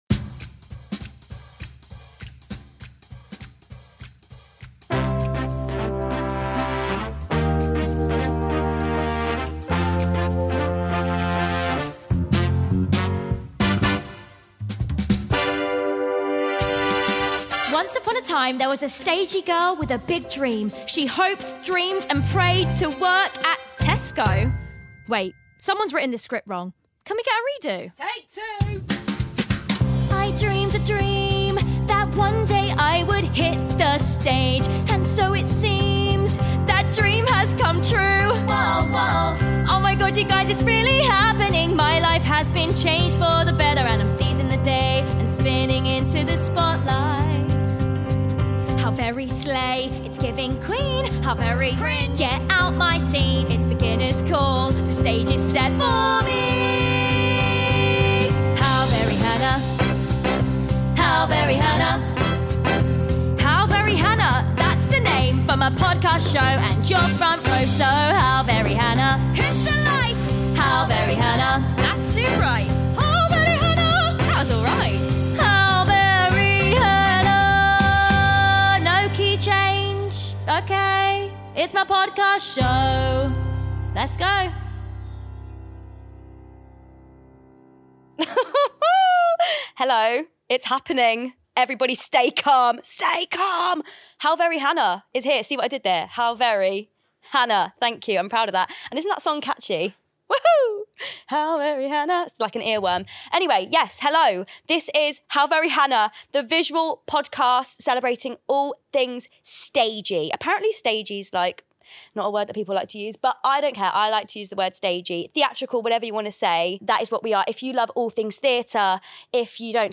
In this first ep, I’m diving headfirst into the chaos: sharing a bit about me (with the help of a few accents), chatting about the unspoken rules of theatre life, and hitting the streets for a cheeky game to test the people of London!!